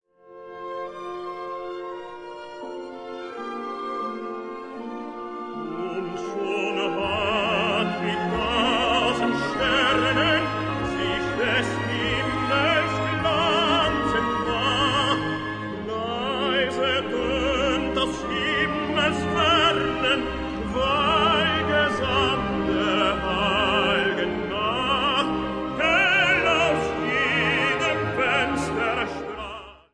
key: F-mayor